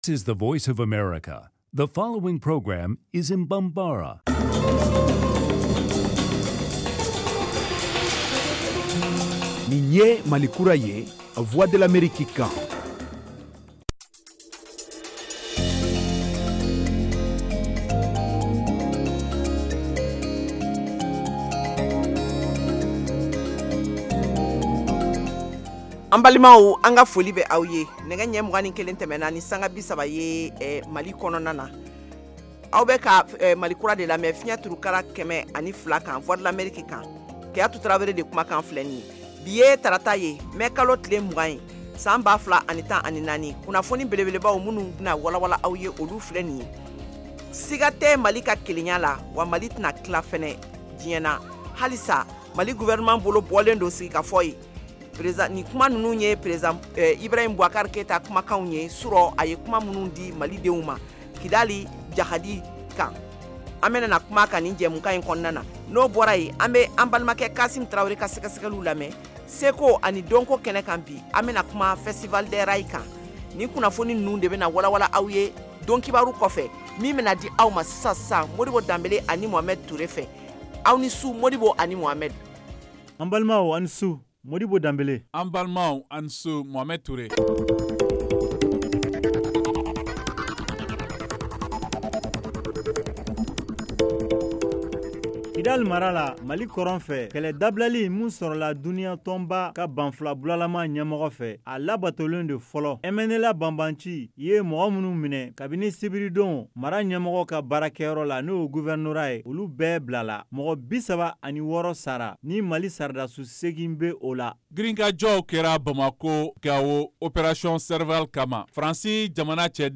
en direct de Washington. Au menu : les nouvelles du Mali, les analyses, le sport et de l’humour.